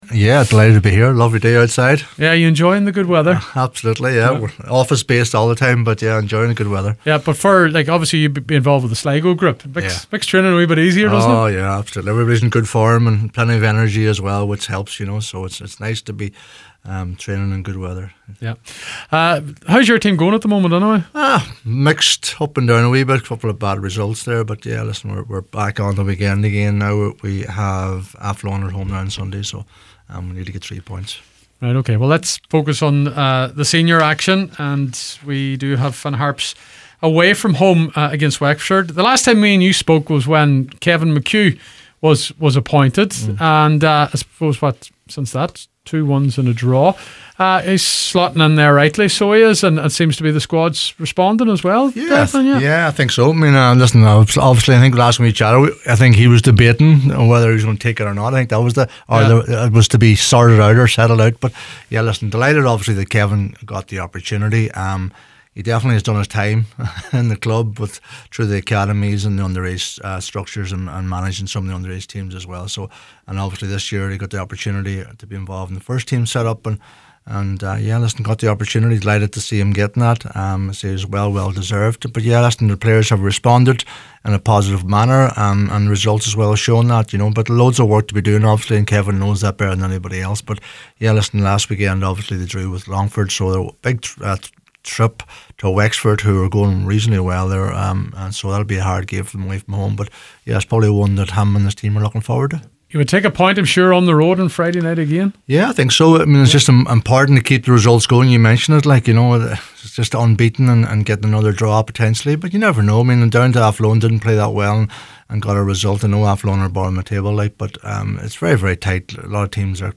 League of Ireland preview